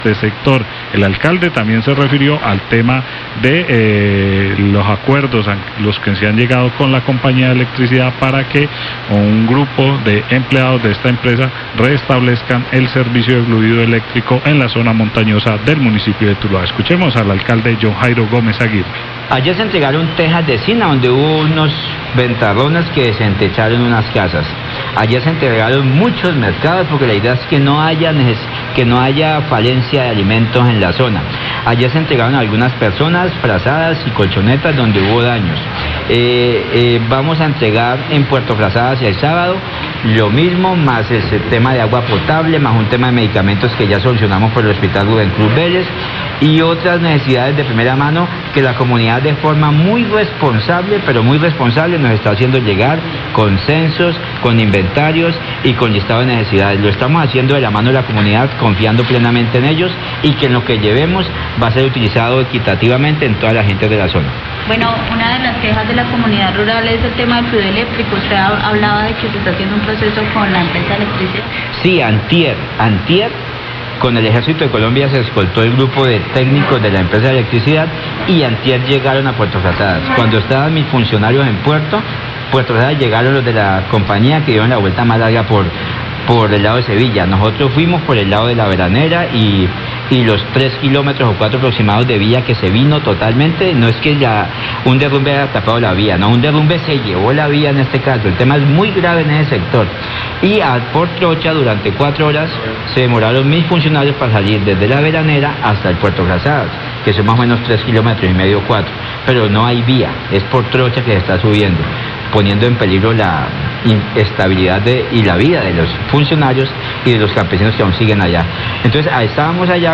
Radio
Alcalde de Tuluá habla de la presencia que están haciendo en la zona rural afectada por las lluvias, así mismo del acompañamiento que el ejército nacional le brinda a los funcionarios de la empresa de energía para que puedan restablecer el servicio.